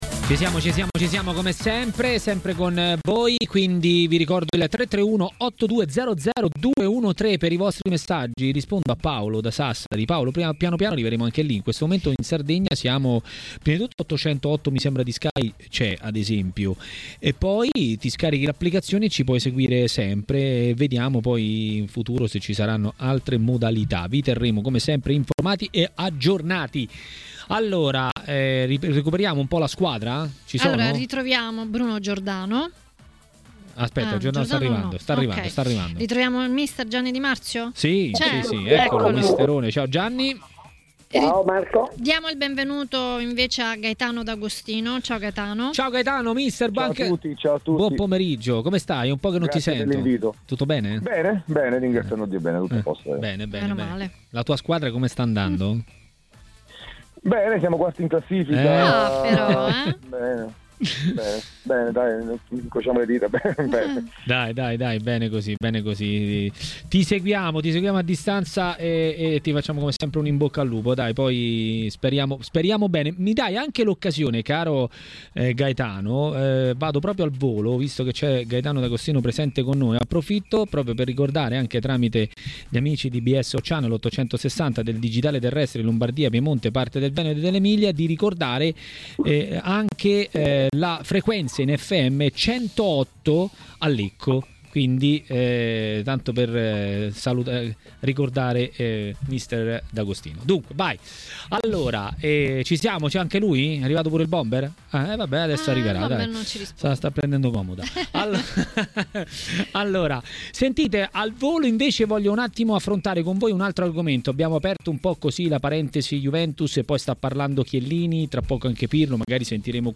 A parlare di coppe e non solo a Maracanà, nel pomeriggio di TMW Radio, è stato l'ex calciatore e tecnico Gaetano D'Agostino.